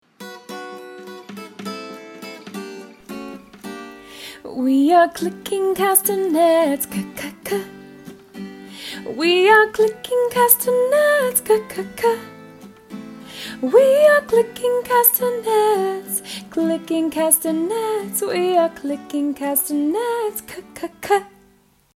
Action and song: ถามนักเรียนว่า จำได้ไหม มึหญิงสาวฝึกเต้นรำและได้ยินเสียงของคาสเซเนส อย่างไรบ้าง จากนั้นเรียนรู้เพลงประจำตัวอักษรเเละท่าทางของตัวอักษร c ครูชวนนักเรียนทำท่าทางของตัวอักษร c 2 รอบ ครูกดเล่นวิดีโอท่าทางให้นักเรียนดู 2 รอบ และเล่นเพลงให้นักเรียนฟัง พร้อมทำท่าทางประกอบ คำแปลเพลง
C-song-v2.mp3